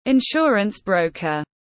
Nhân viên môi giới bảo hiểm tiếng anh gọi là insurance broker, phiên âm tiếng anh đọc là /ɪnˈʃɔː.rəns brəʊ.kər/.
Insurance broker /ɪnˈʃɔː.rəns brəʊ.kər/
Insurance-broker.mp3